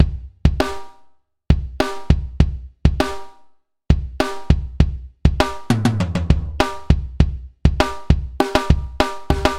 欣欣然原声大鼓12
描述：这是一个比我以前的鼓声更轻的原声鼓线，用于Rnb和灵魂歌曲中。
标签： 140 bpm Hip Hop Loops Drum Loops 1.62 MB wav Key : Unknown
声道立体声